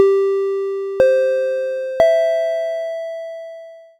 3-tone chime UP
bell chime ding microphone pa ping ring sound effect free sound royalty free Sound Effects